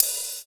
95 OP HAT.wav